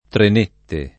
[ tren % tte ]